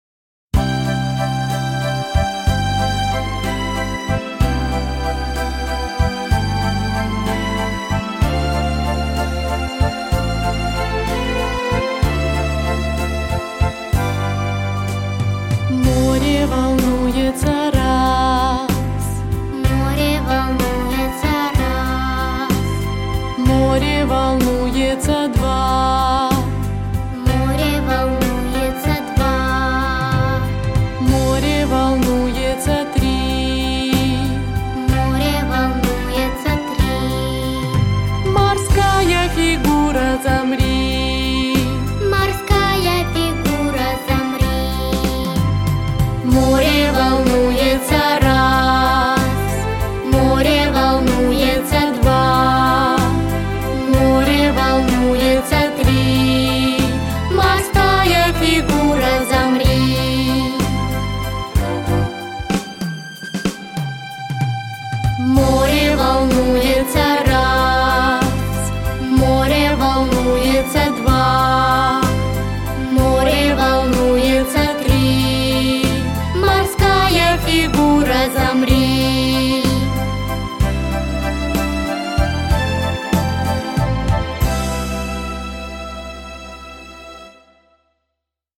• Категория: Детские песни
распевки